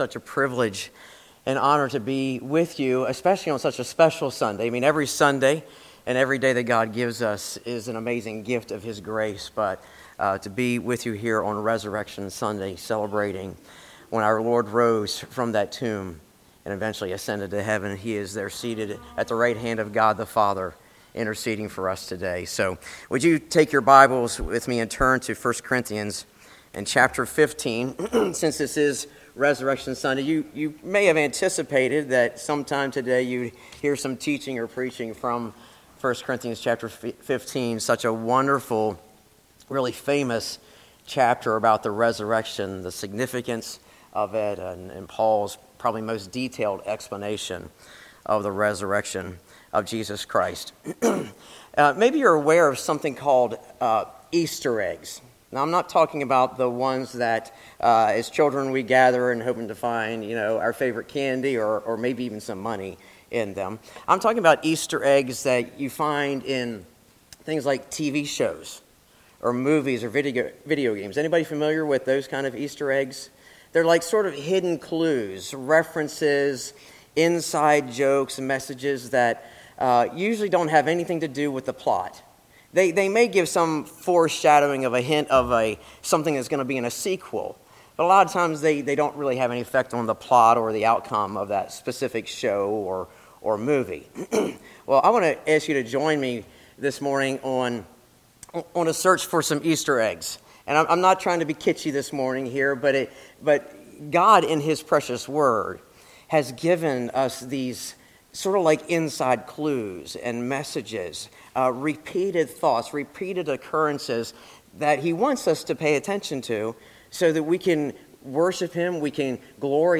Audio recordings of sermons preached at James River Community Church.